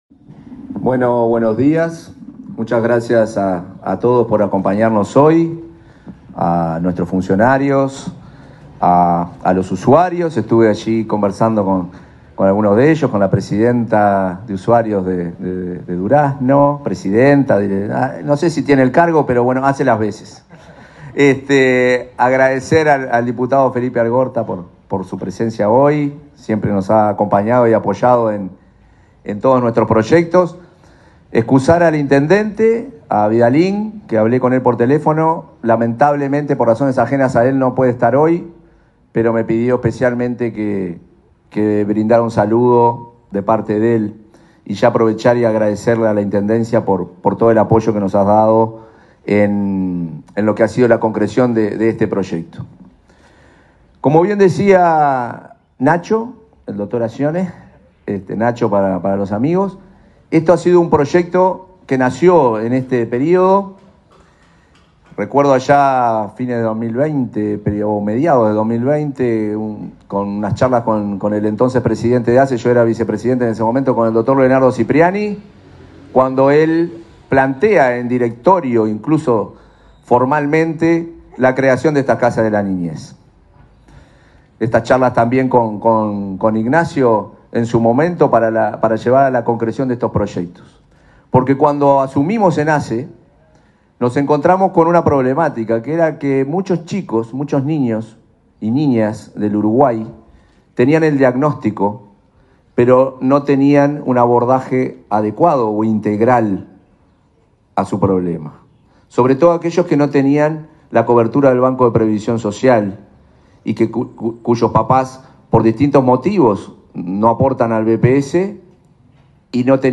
Palabras del presidente de ASSE, Marcelo Sosa
Palabras del presidente de ASSE, Marcelo Sosa 12/11/2024 Compartir Facebook X Copiar enlace WhatsApp LinkedIn Este martes 12, el presidente de la Administración de los Servicios de Salud del Estado (ASSE), Marcelo Sosa, encabezó la inauguración de una casa de desarrollo de la niñez en Durazno.